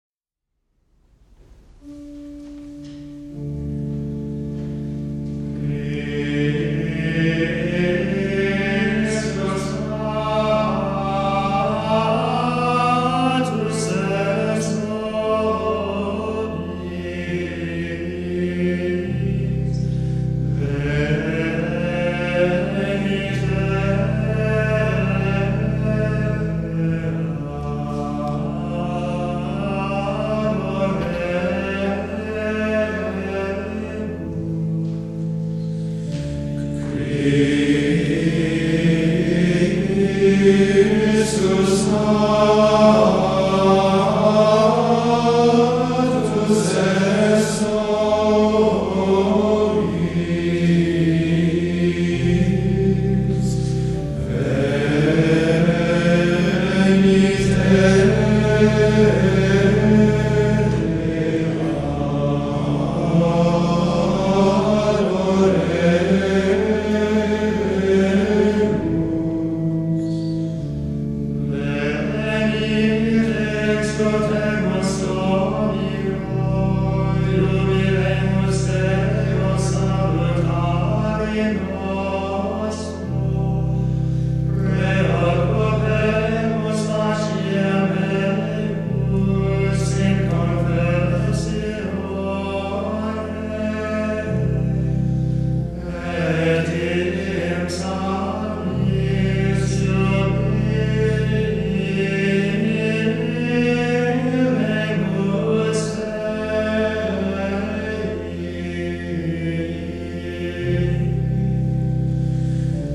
CD-Quality, Windows Media (WMA) Format
from the invitatory for Matins on Christmas